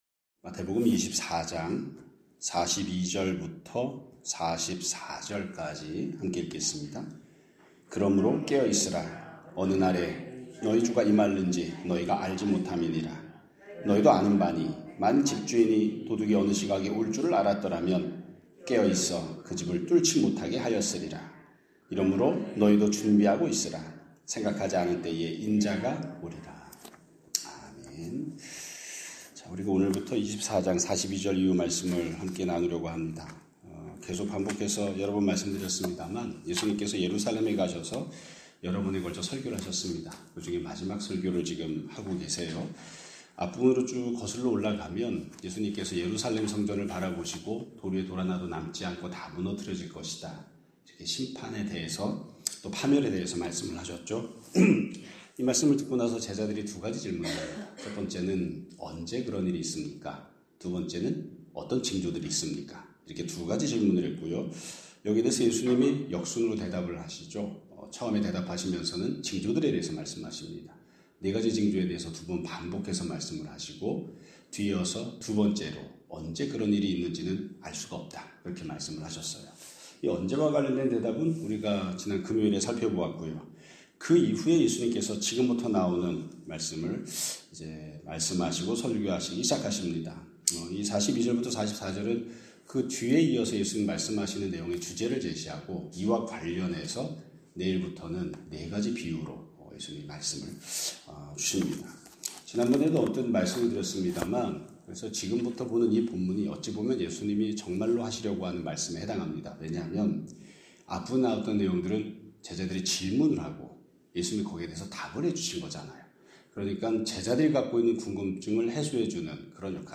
2026년 3월 16일 (월요일) <아침예배> 설교입니다.